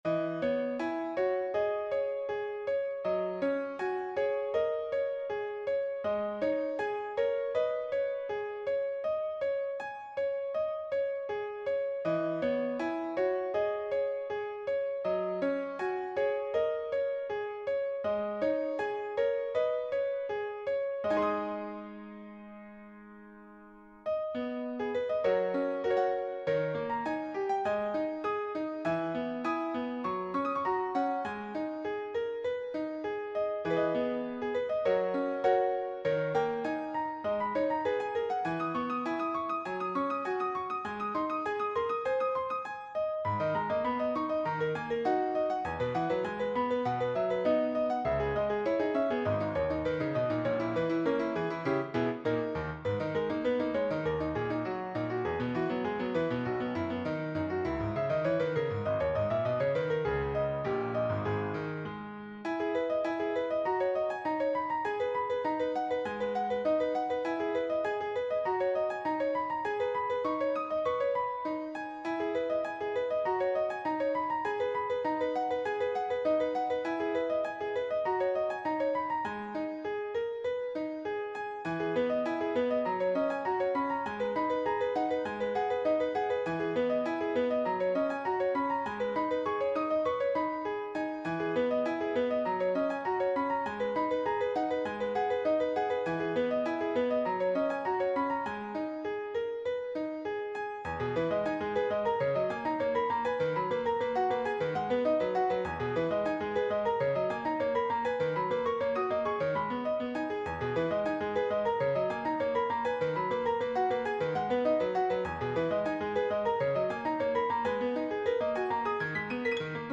照着 这个谱子 一个音符一个音符扣到 MuseScore 里，便得到了下面这段音频
应该说作为改编的钢琴独奏，其还原度还是相当高的